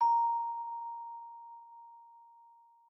celesta1_1.ogg